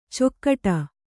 ♪ cokkaṭa